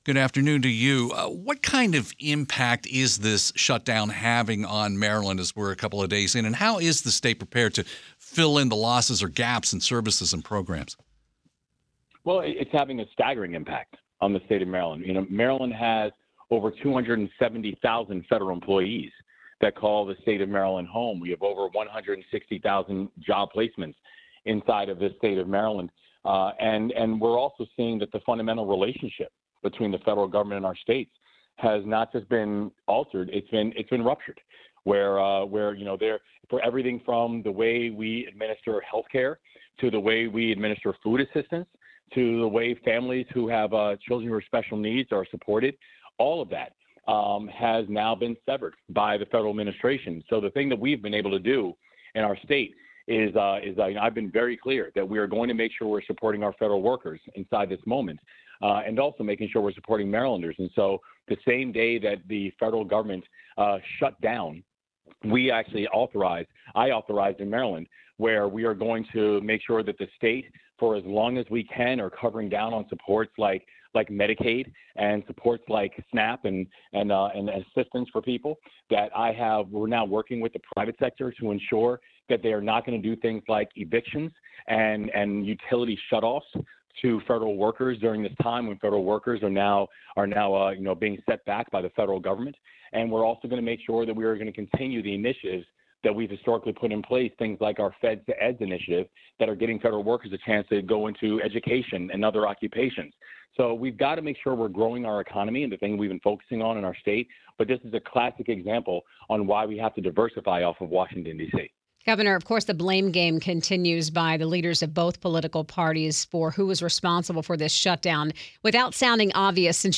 Maryland Gov. Wes Moore talks about the impact of the government shutdown on his state